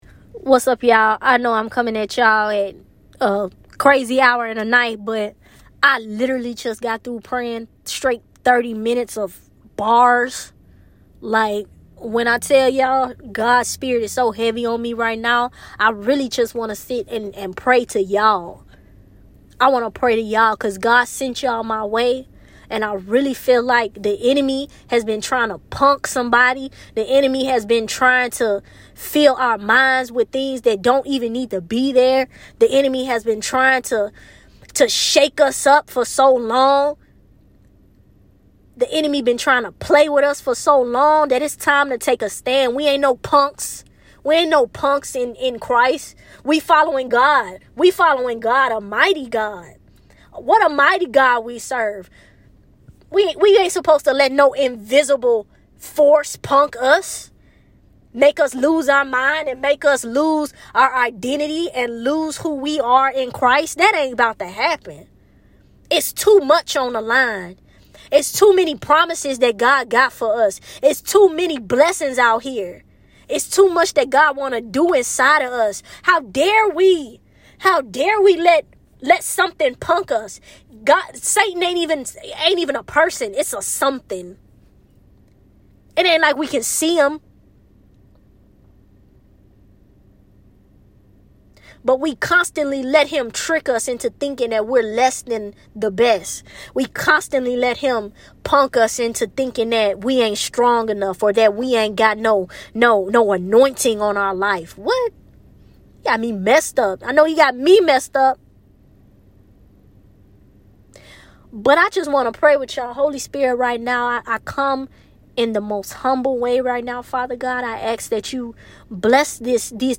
I’m literally in my car praying straight bars cus the enemy been playing with us for tooooo long! This prayer is for YALL!